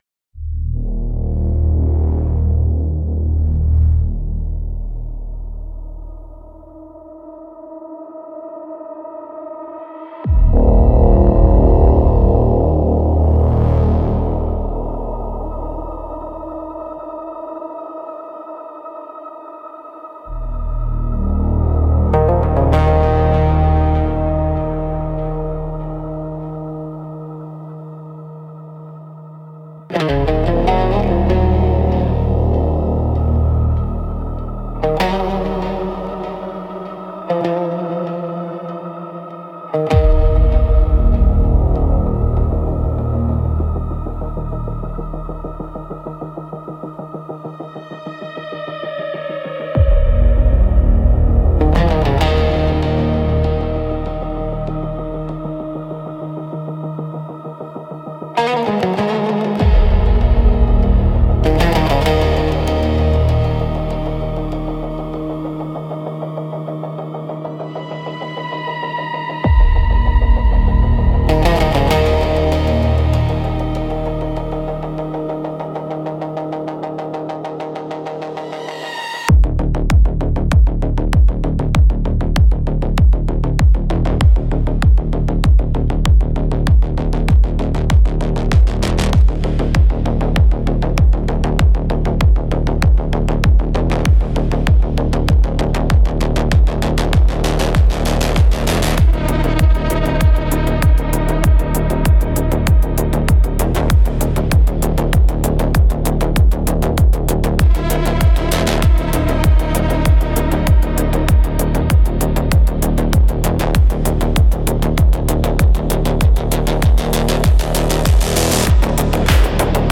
Instrumental - An Elegy for the Assembly Line 4.43